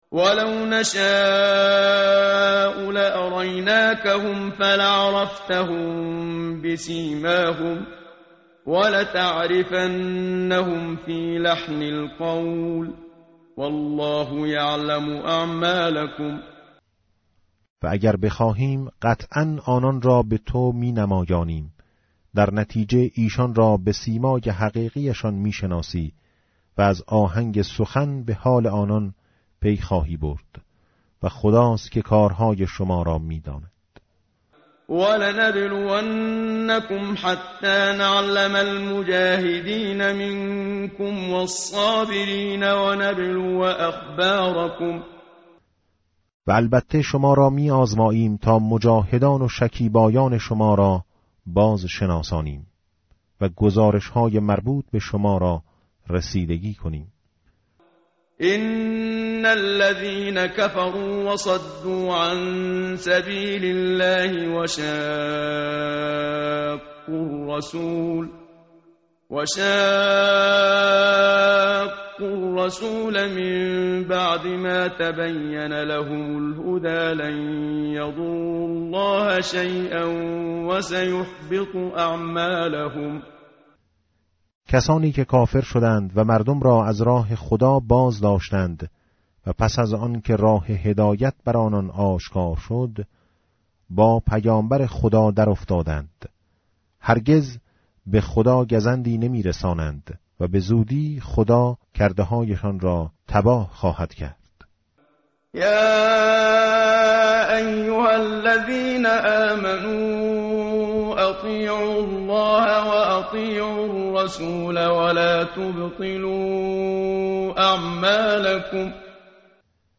tartil_menshavi va tarjome_Page_510.mp3